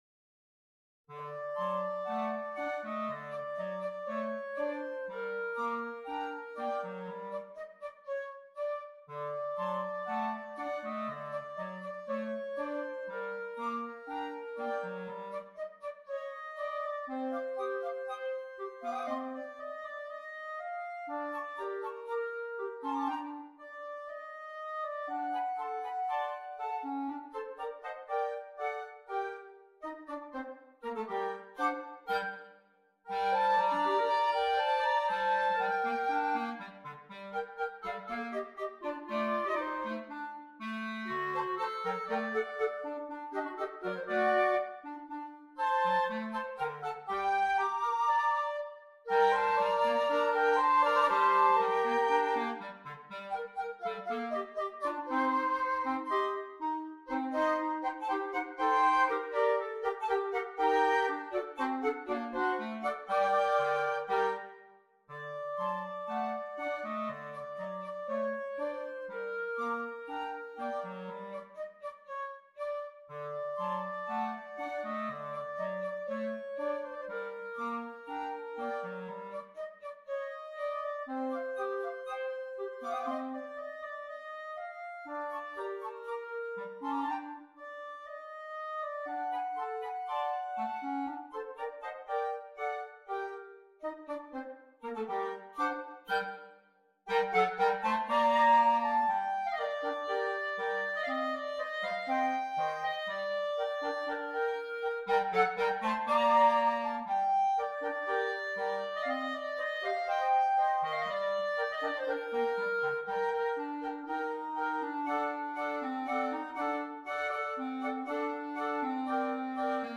Voicing: 2 Flute and 2 Clarinet